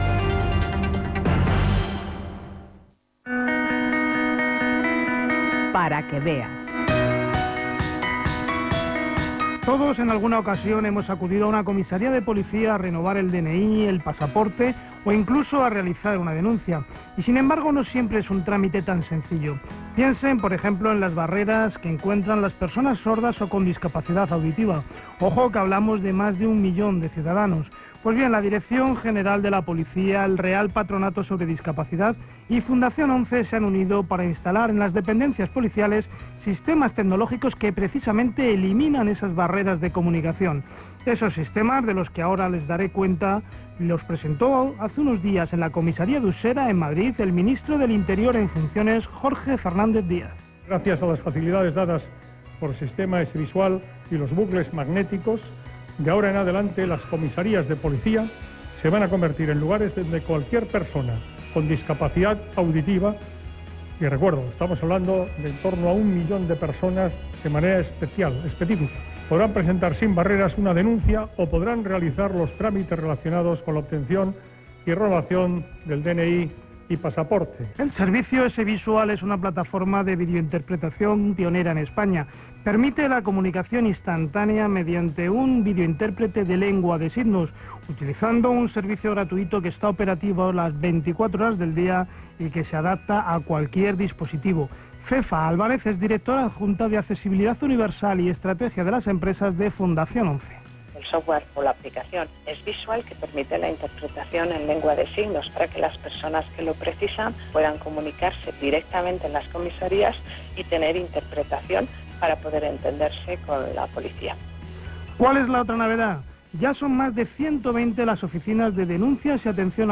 Enlace al programa ‘Para que Veas’, de Radio 5, con declaraciones de los protagonistas